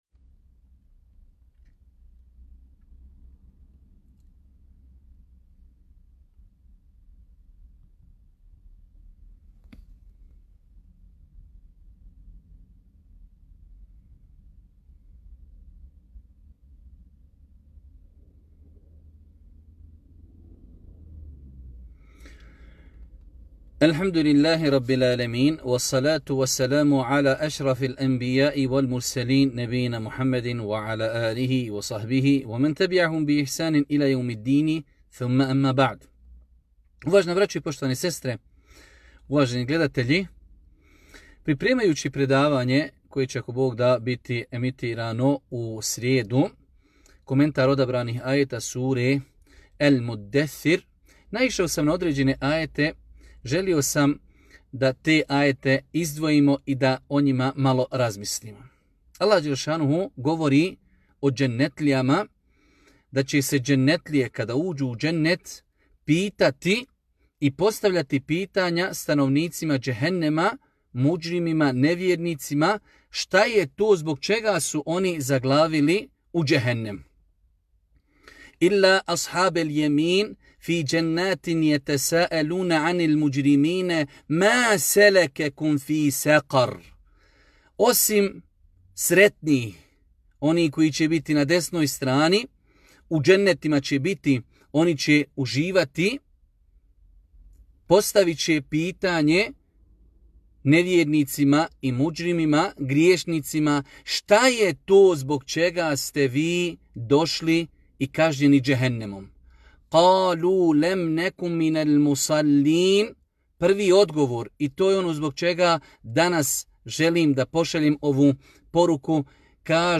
Odgovor iz kratkog predavanja